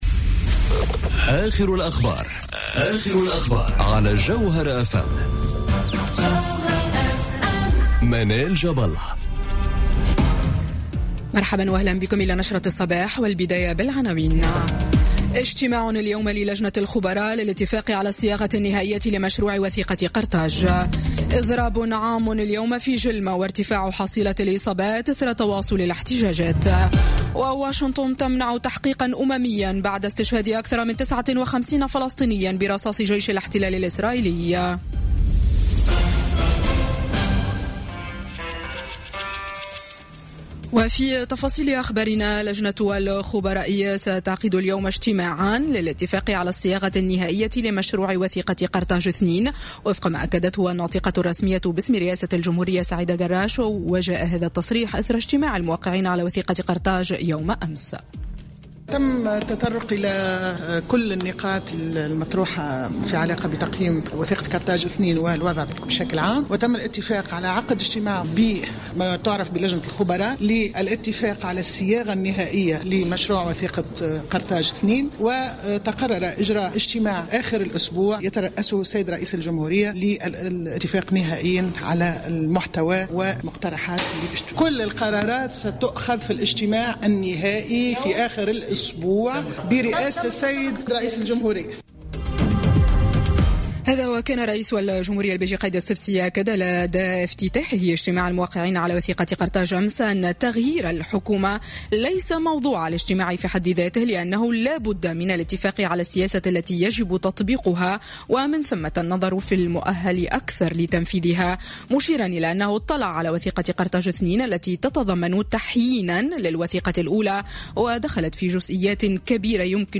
نشرة أخبار السابعة صباحا ليوم الثلاثاء 15 ماي 2018